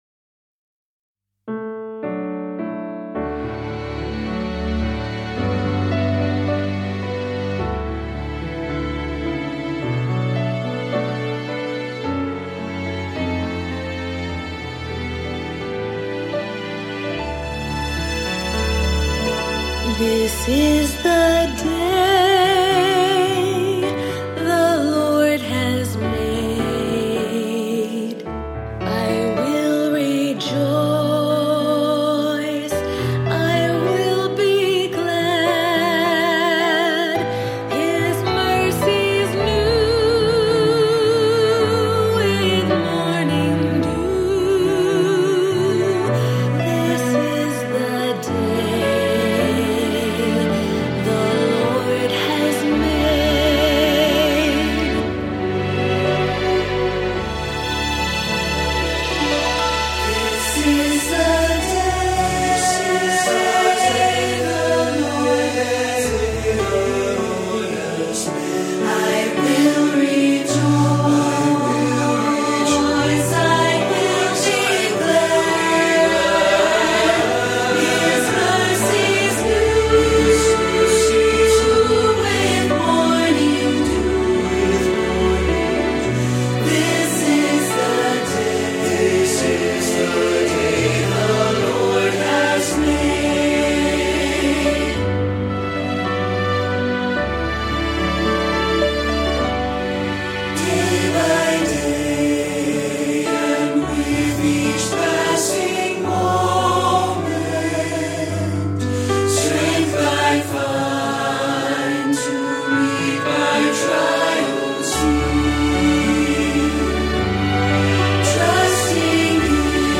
Set to an early American tune you will easily recognize.
Accompaniment Tracks, Listening Demo, Sheet Music